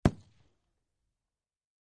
HK-21 Machine Gun Single Shot From Medium Point of View, X4